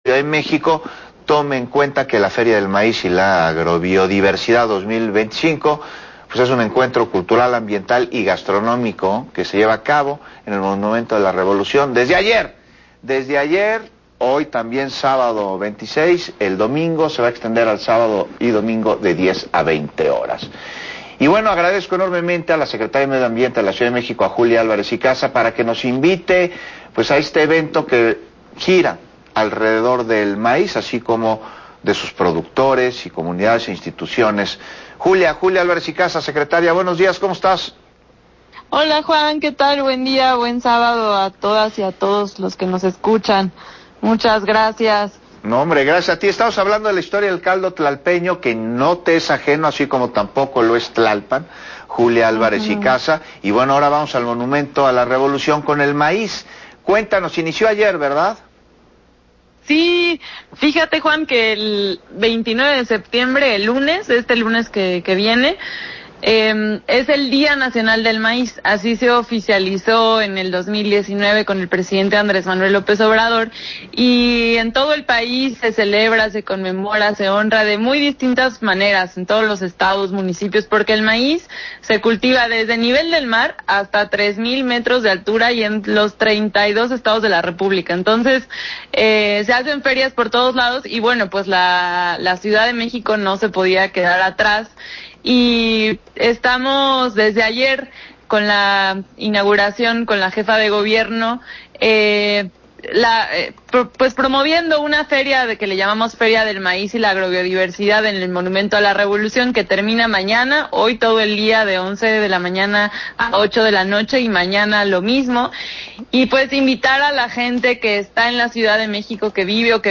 Entrevista a Julia Álvarez Icaza, secretaria del Medio Ambiente de la Ciudad de México, sobre el Festival del Maíz